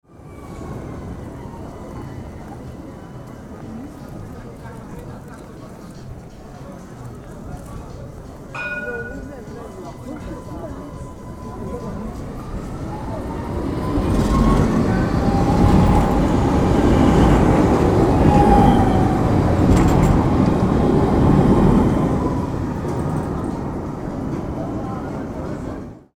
Tram Arrival, Stop, And Departure Sound Effect
Description: Tram arrival, stop, and departure sound effect. Streetcar sound in an urban environment. Street sounds. City noises.
Tram-arrival-stop-and-departure-sound-effect.mp3